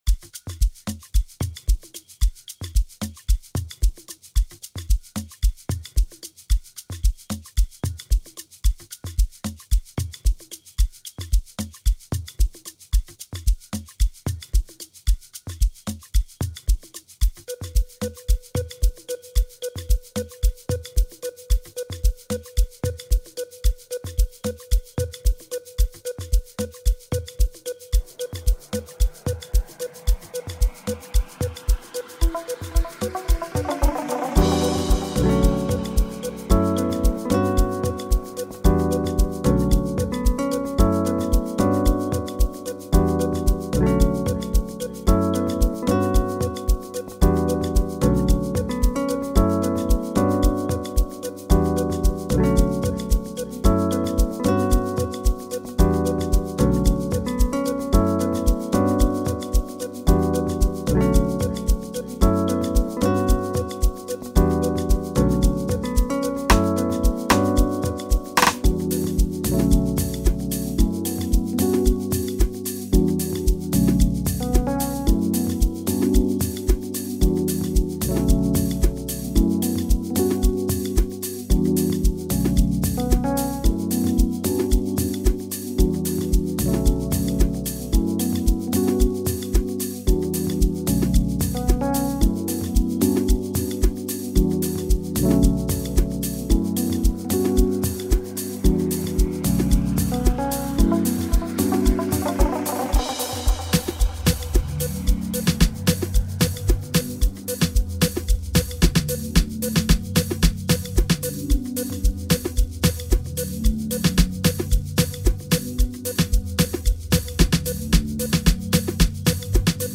beautiful Private School Amapiano melody